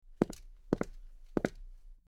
Footsteps On Concrete 02
Footsteps_on_concrete_02.mp3